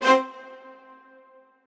strings3_5.ogg